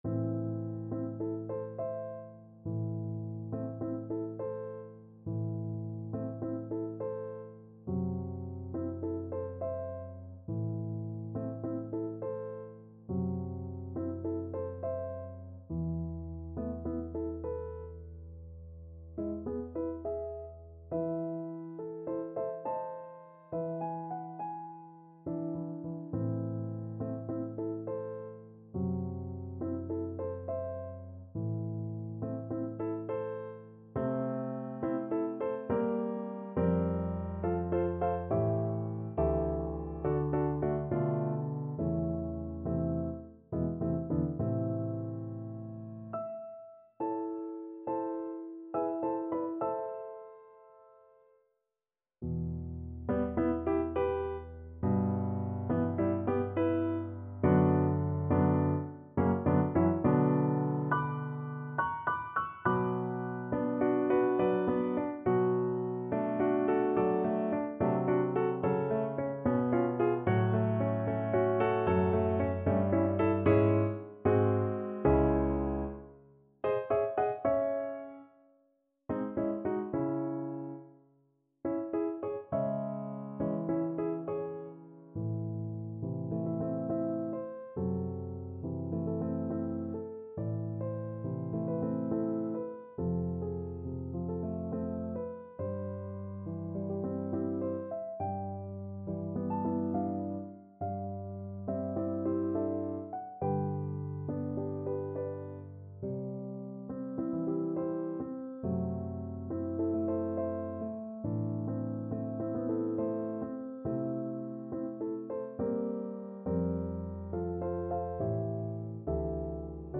Andante =69